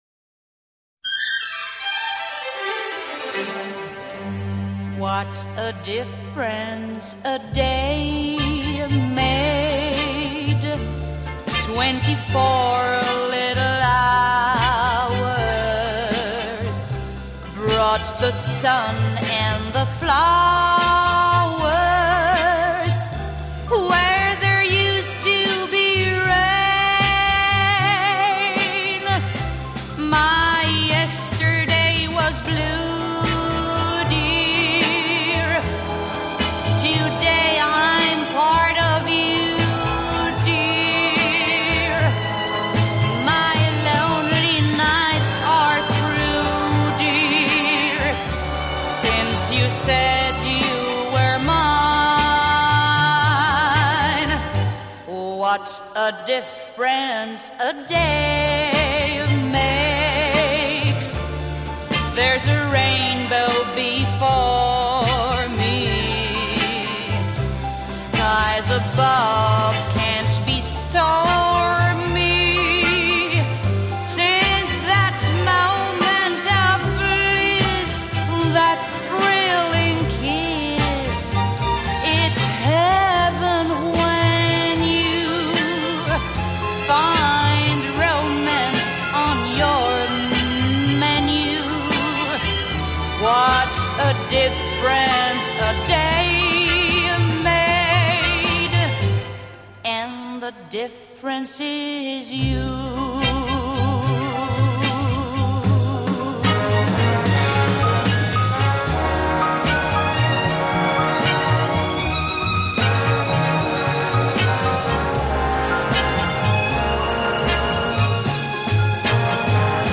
由于是早年录音，为保持原音，故以 WAV 单曲格式 陆续登出，与网友共享